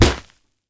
punch_grit_wet_impact_04.wav